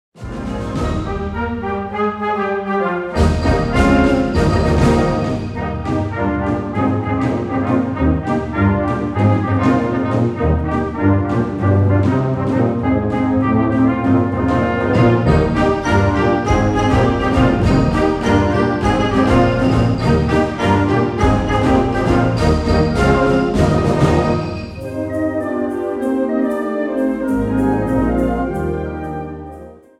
Catégorie Harmonie/Fanfare/Brass-band
Sous-catégorie Ouvertures (œuvres originales)
Instrumentation Ha (orchestre d'harmonie)